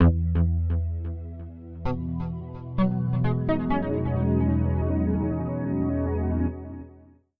MinderiaOS Beta Startup.wav